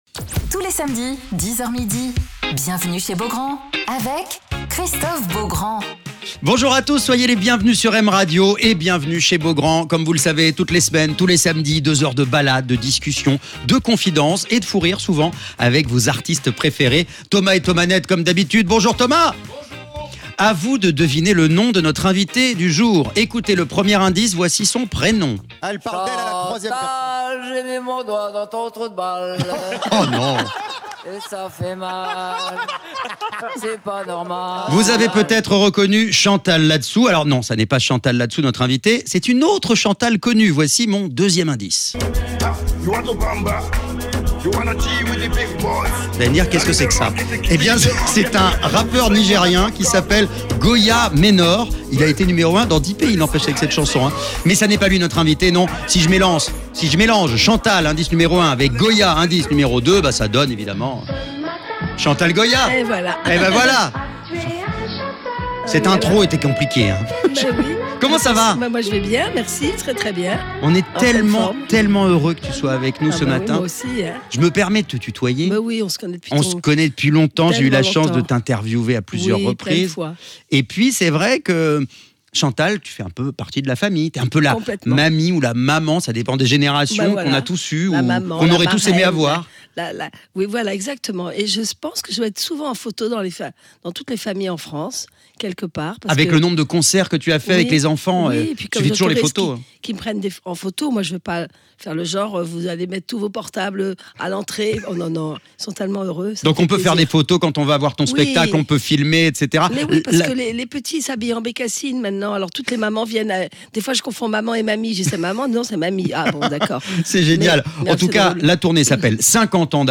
Alors qu'elle est actuellement en tournée, Chantal Goya est l'invitée de Christophe Beaugrand sur M Radio